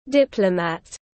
Nhà ngoại giao tiếng anh gọi là diplomat, phiên âm tiếng anh đọc là /ˈdɪp.lə.mæt/.
Diplomat /ˈdɪp.lə.mæt/